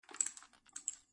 GrenadeReload.wav